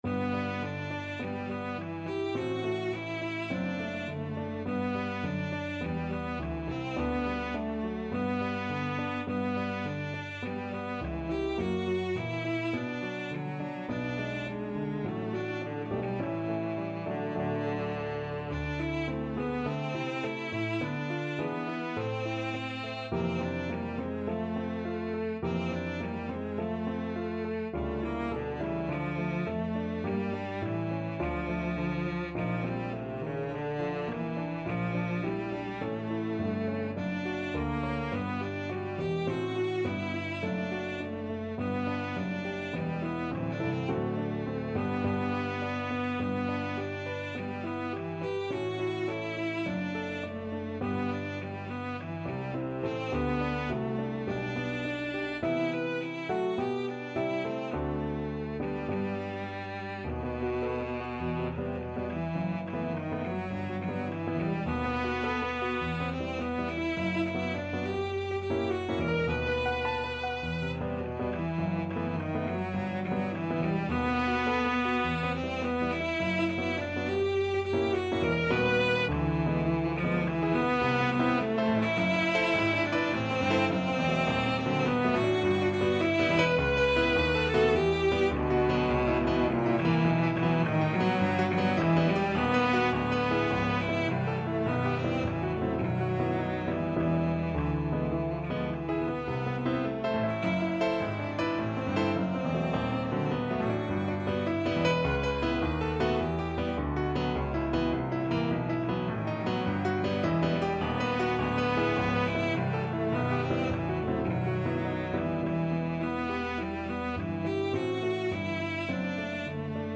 Einfach, innig =104
4/4 (View more 4/4 Music)
Classical (View more Classical Cello Music)